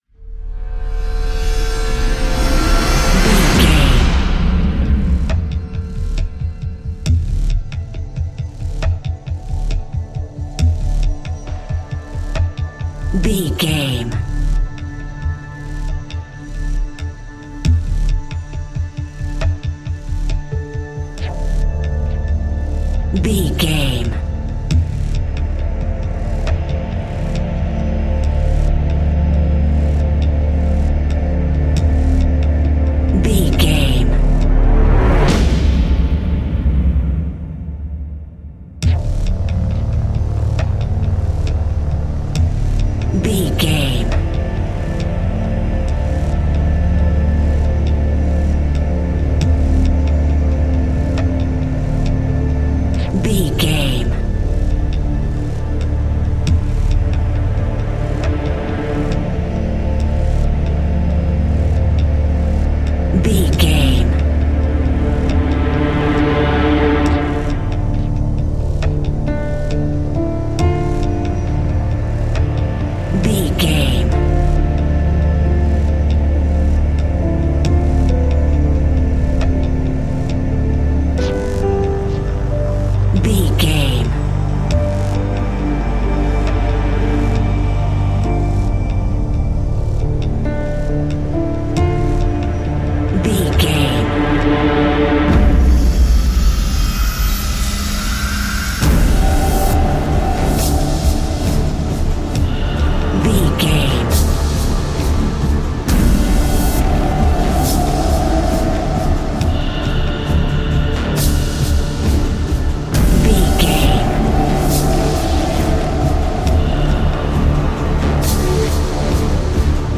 Video Game